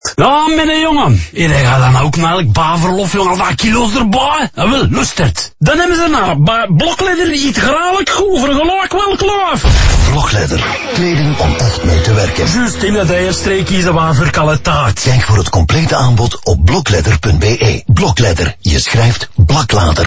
Telkens worden scenario’s uitgewerkt waarin bouwvakkers in dialect met elkaar communiceren, van West-Vlaams tot Kempisch en Limburgs.
• Aalsters
Aalsters.mp3